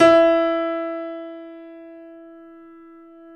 Index of /90_sSampleCDs/Roland - Rhythm Section/KEY_YC7 Piano mf/KEY_mf YC7 Mono
KEY E 3 F 0C.wav